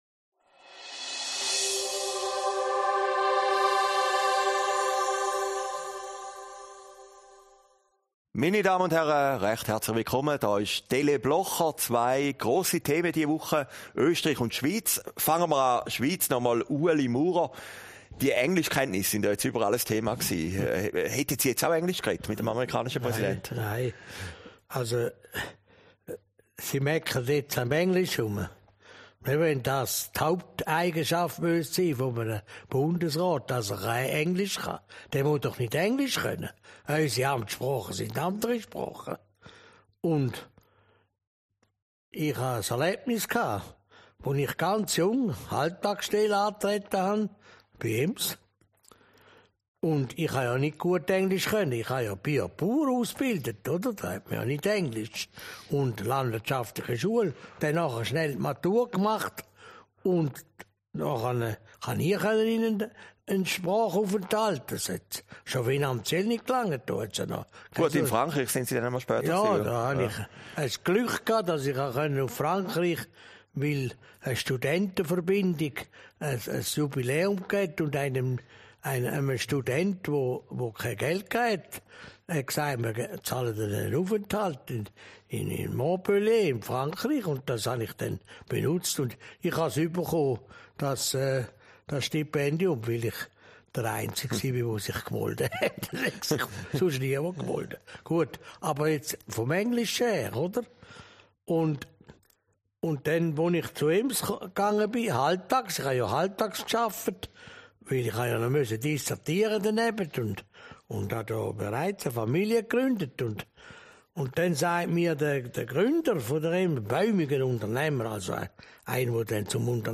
Aufgezeichnet in Herrliberg, 24. Mai 2019